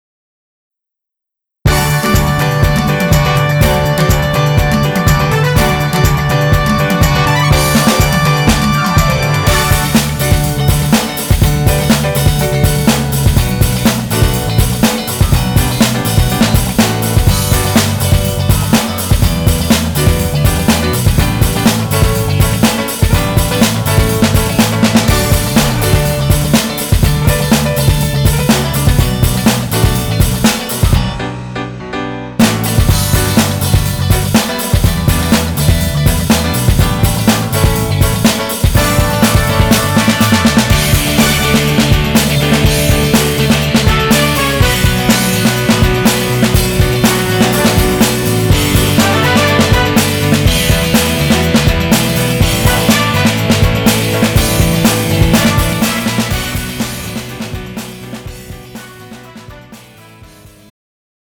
장르 축가 구분 Pro MR